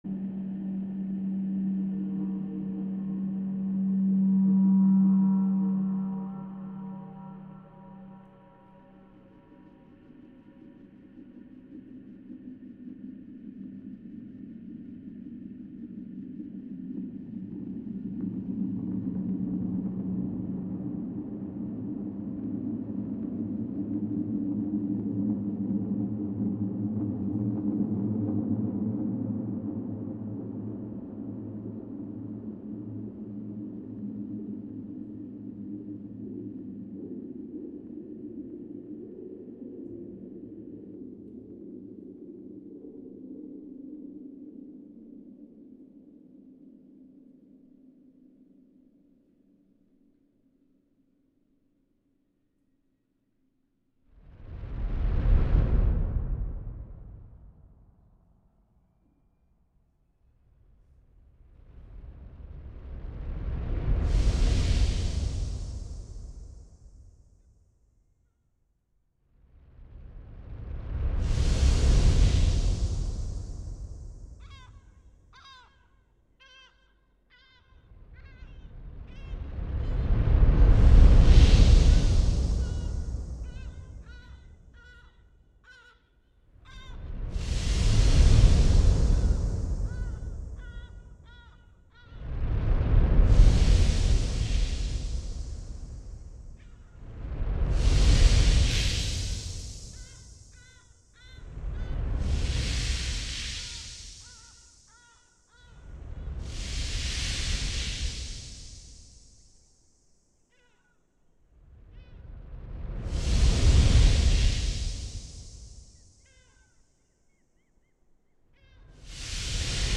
Live from Fridman Gallery: CT::SWaM ExChange014 (Audio)